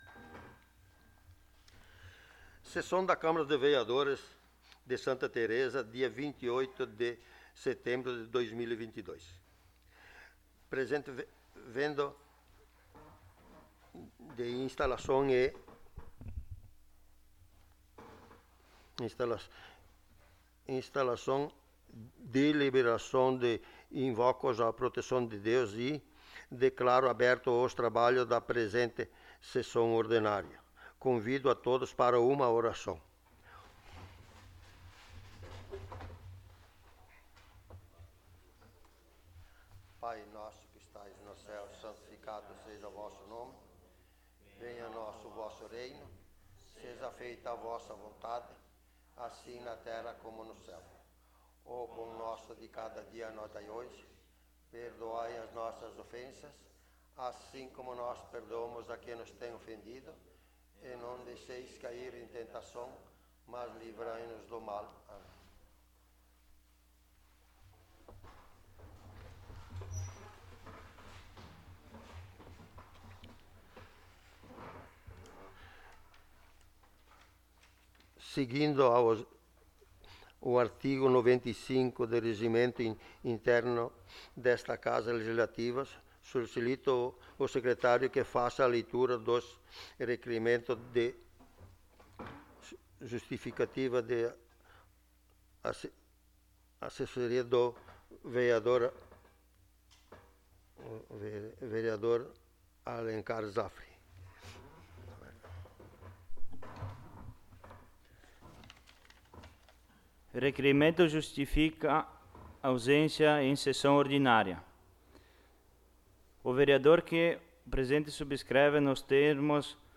16ª Sessão Ordinária de 2022
Local: Plenário Pedro Parenti